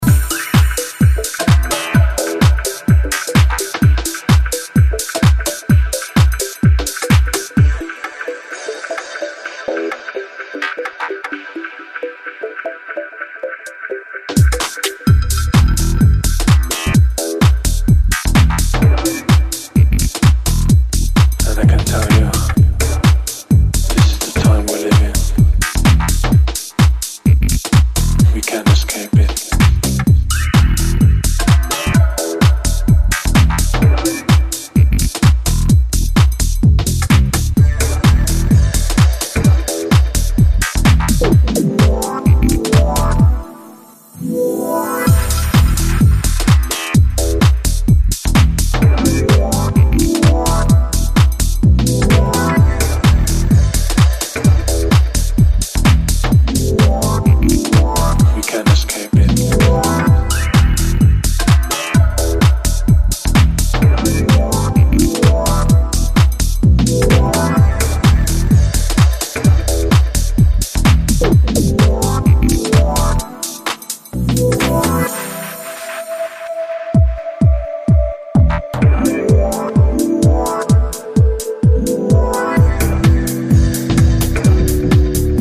more electro-tinged territories than his previous material
modern house music